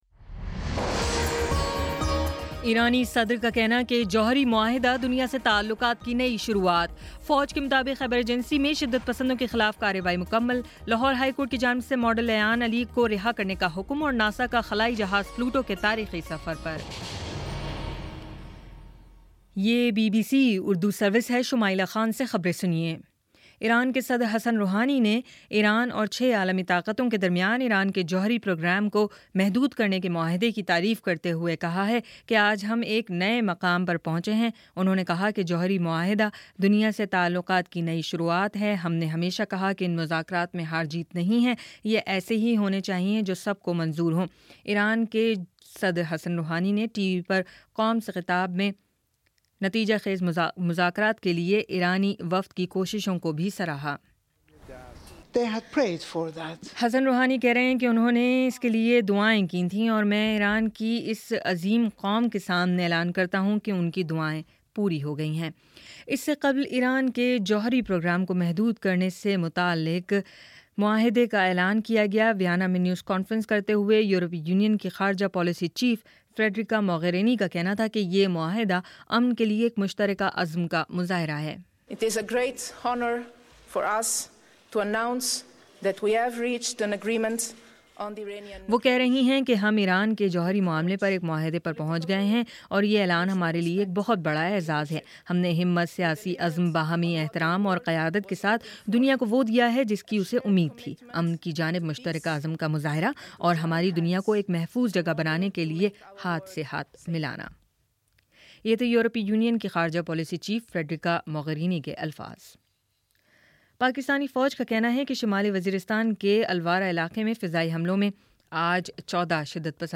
جولائی 14: شام چھ بجے کا نیوز بُلیٹن